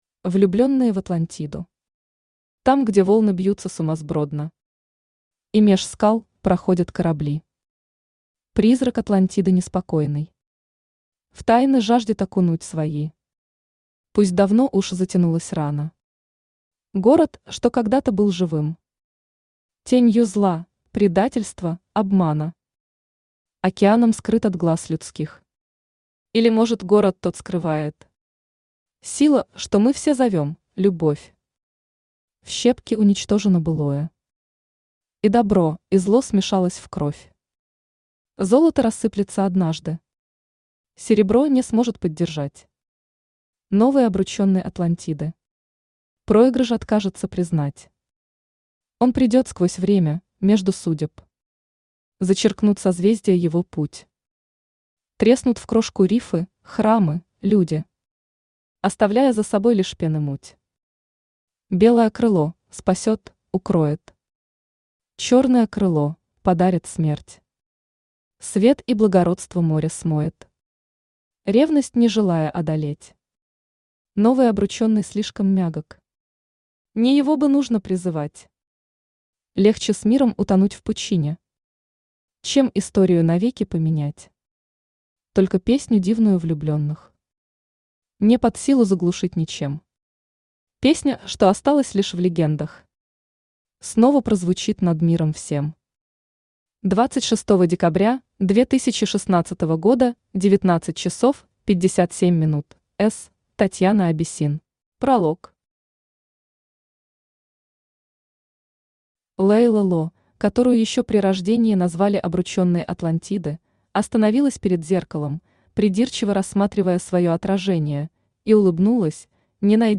Aудиокнига Влюбленные в Атлантиду Автор Татьяна Абиссин Читает аудиокнигу Авточтец ЛитРес.